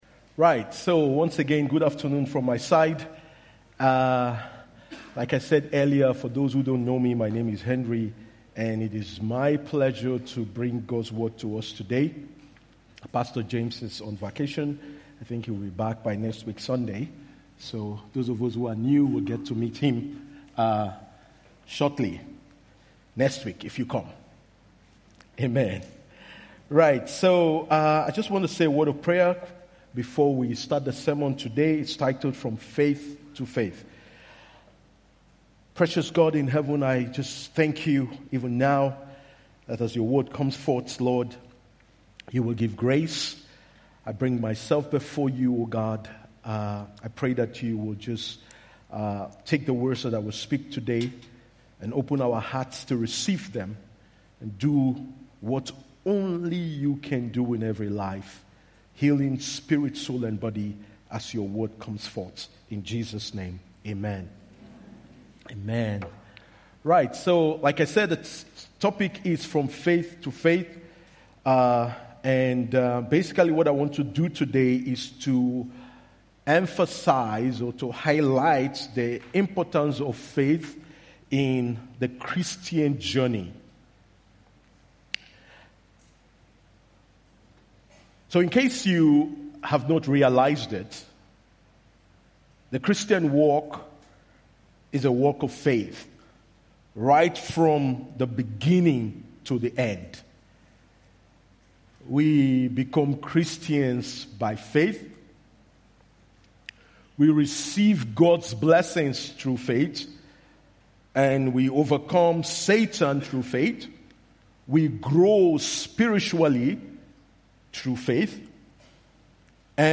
IBC Hamburg Predigt